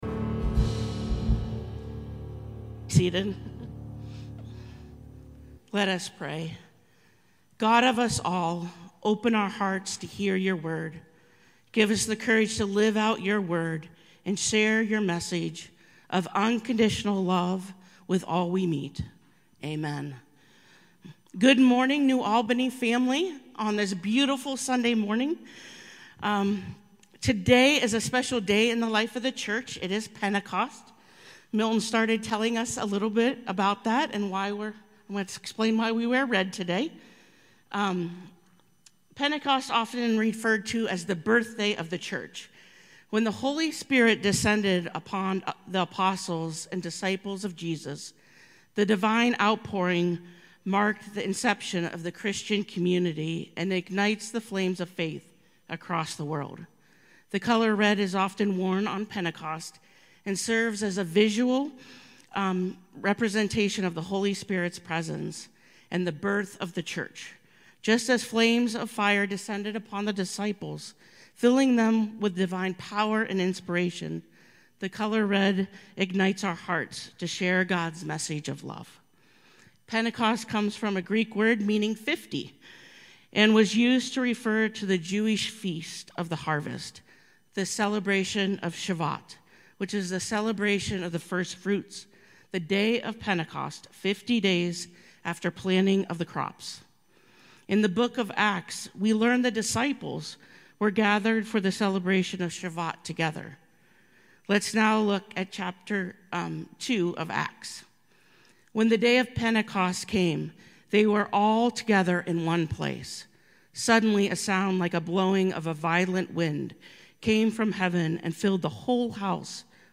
May 19, 2024 Sermon